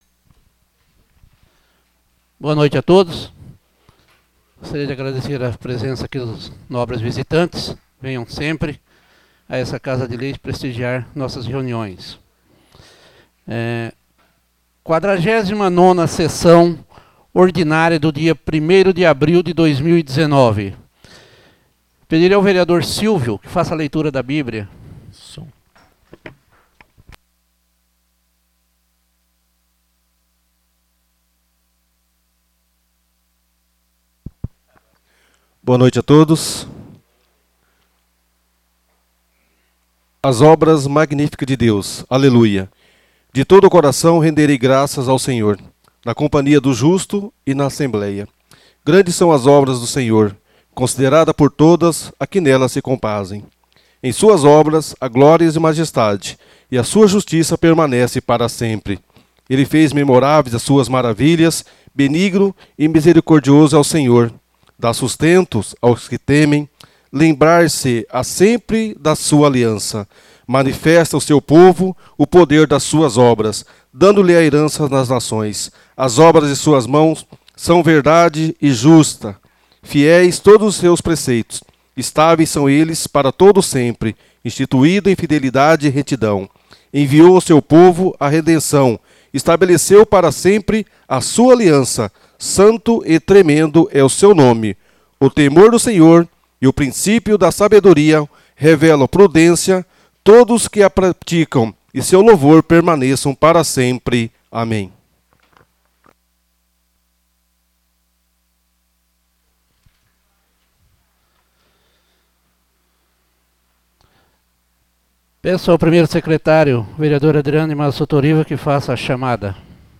Áudio Sessão Ordinária 49/2019